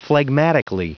Prononciation du mot phlegmatically en anglais (fichier audio)